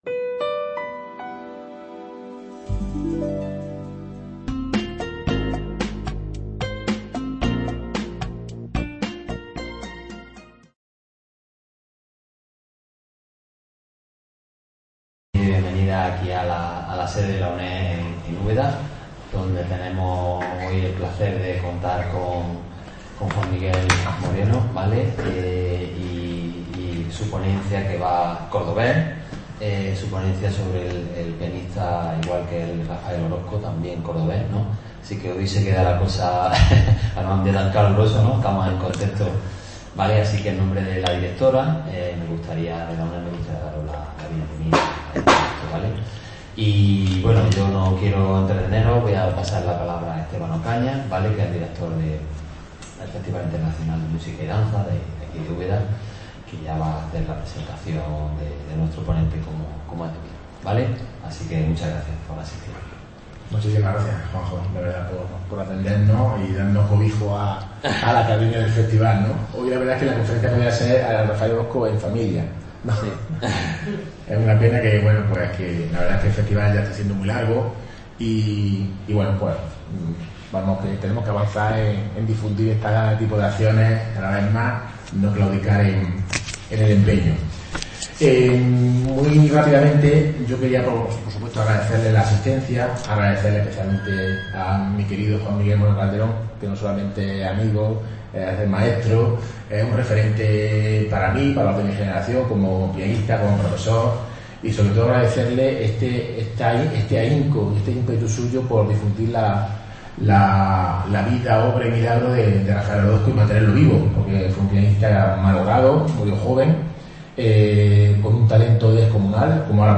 Una conferencia en la que hemos contado con la asistencia del maestro Joaquín Achúcarro, a quien le unía una relación de amistad con Orozco.